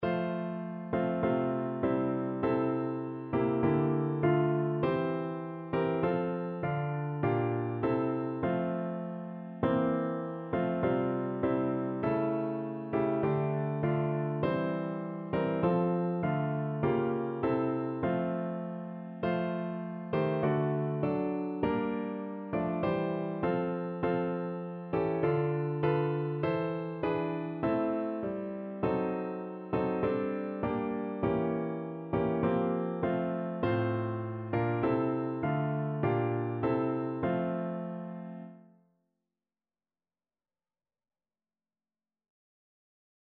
No parts available for this pieces as it is for solo piano.
4/4 (View more 4/4 Music)
F major (Sounding Pitch) (View more F major Music for Piano )
Piano  (View more Intermediate Piano Music)
Christian (View more Christian Piano Music)
round_the_lord_PNO.mp3